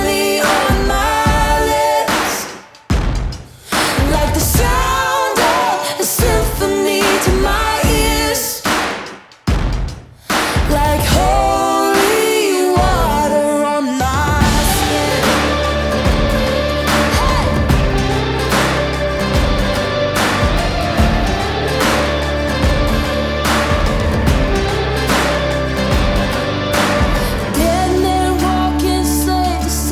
Genre: Christian & Gospel